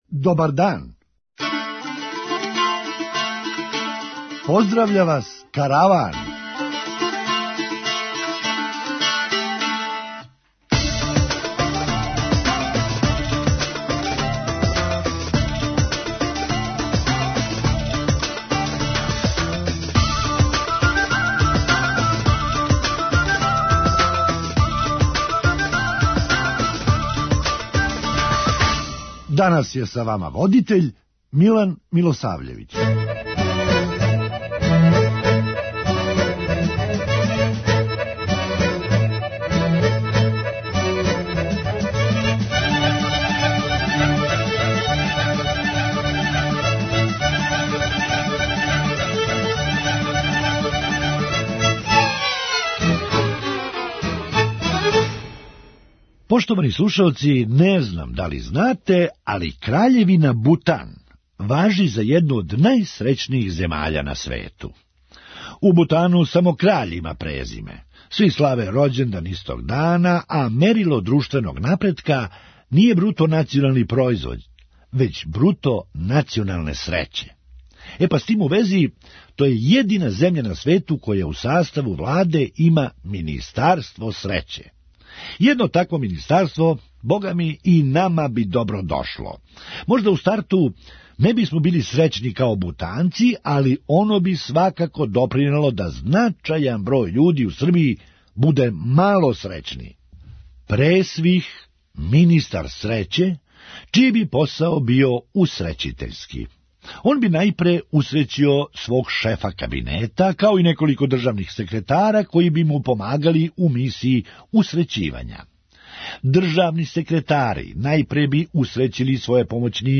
Хумористичка емисија
Иако је реч о само једном иксу, за пешаке је овај прелаз једначина са више непознатих. преузми : 9.59 MB Караван Autor: Забавна редакција Радио Бeограда 1 Караван се креће ка својој дестинацији већ више од 50 година, увек добро натоварен актуелним хумором и изворним народним песмама.